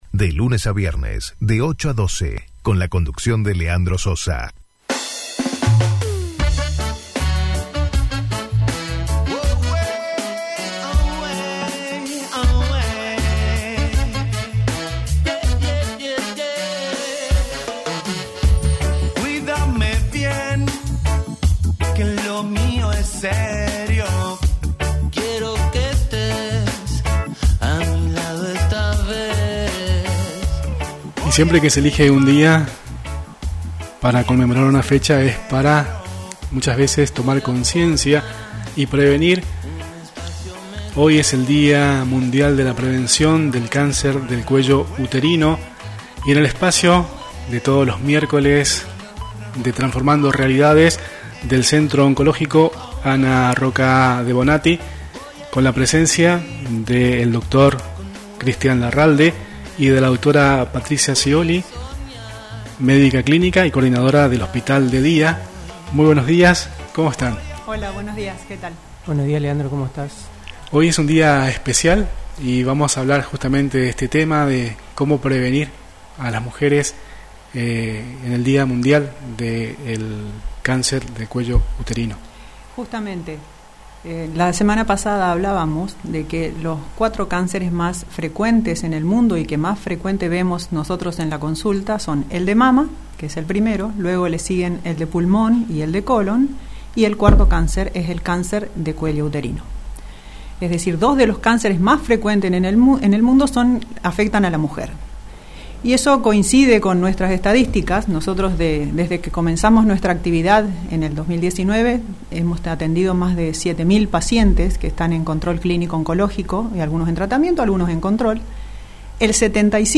En el espacio radial de hoy, abordamos un tema crucial en relación con la prevención y la detección temprana del cáncer de cuello uterino.